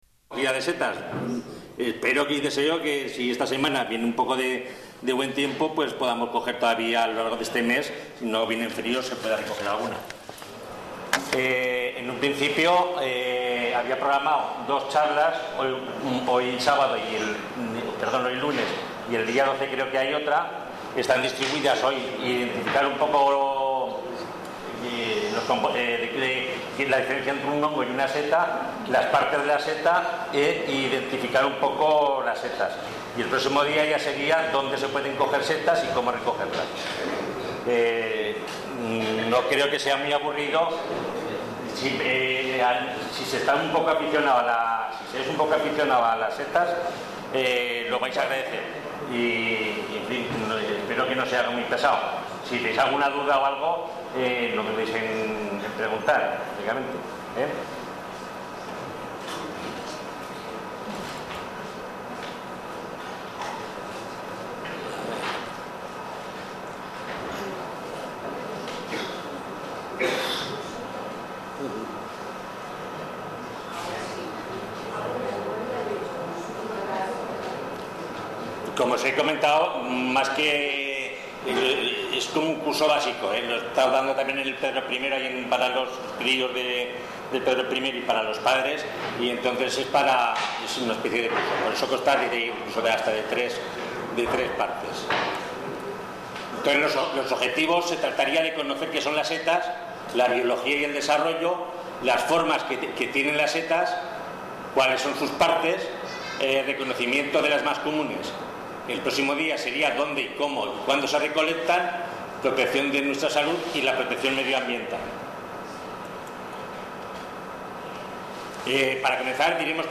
Fichero sonoro mp3 con el contenido de la charla: